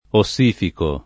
ossificare v.; ossifico [ o SS& fiko ], ‑chi